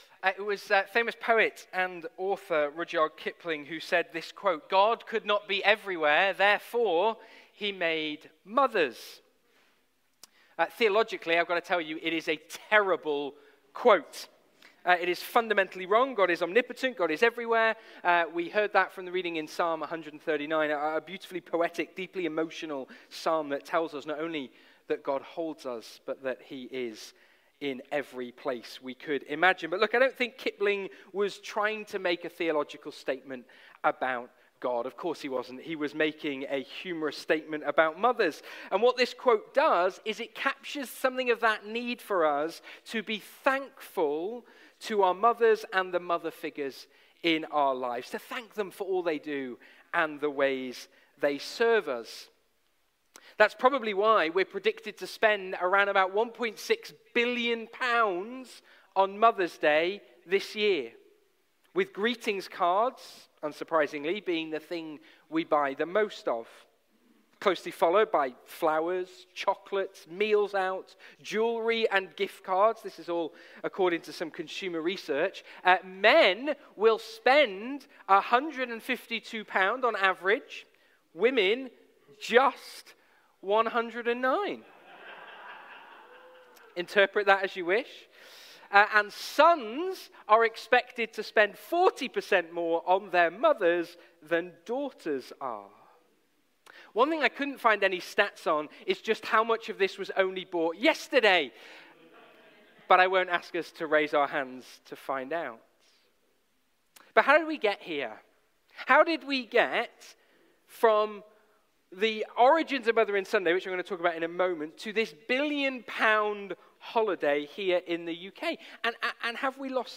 Passage: Matthew 25:34-40, John 19:28-30 Service Type: Sunday Morning